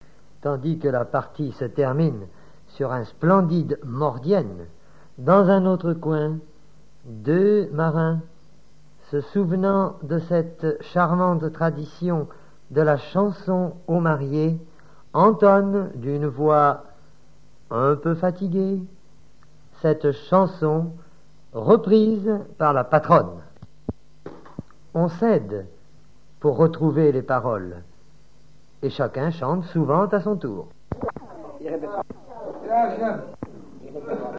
Reportage Folklore vivant - Chanson de mariage
chanteur(s), chant, chanson, chansonnette